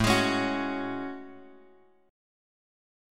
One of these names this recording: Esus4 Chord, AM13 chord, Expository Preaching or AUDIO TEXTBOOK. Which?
AM13 chord